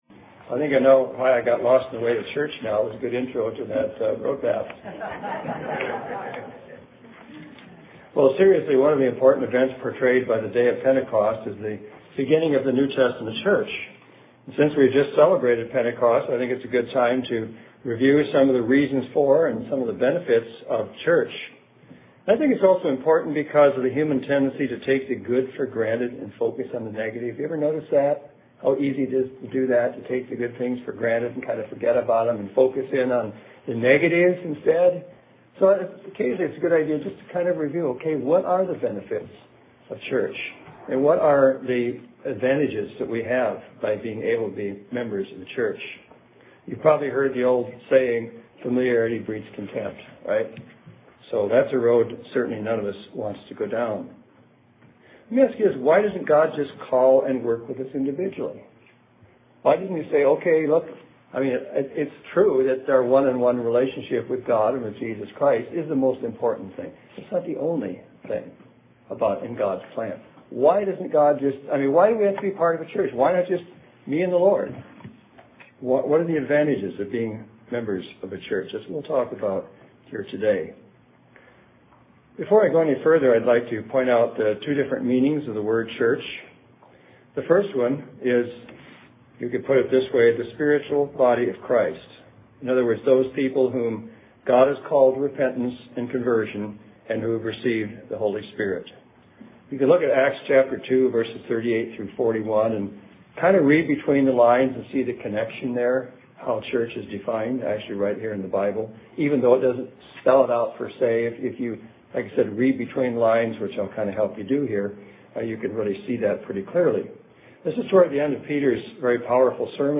UCG Sermon Notes Benefits of Church Bend 6-11 Medford 6-18 Introduction – One of the important events portrayed by the Day of Pentecost is the beginning of the NT Church.